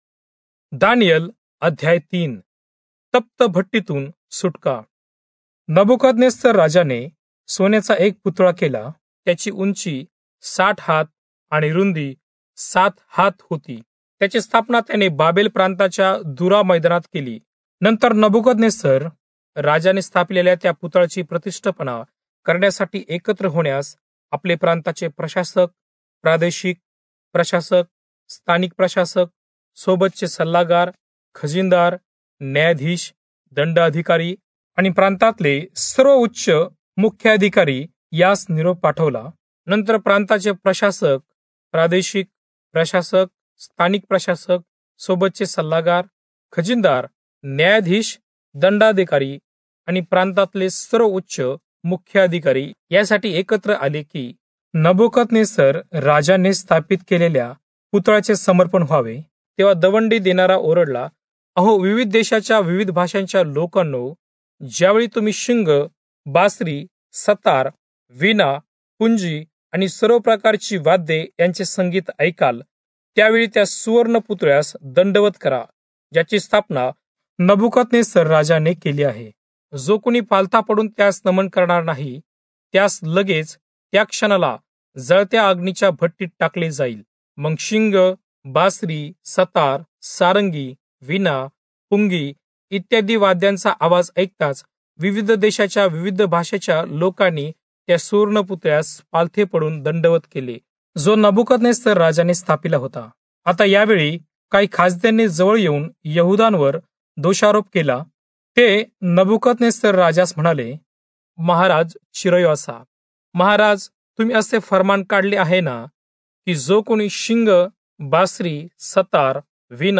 Marathi Audio Bible - Daniel 8 in Irvmr bible version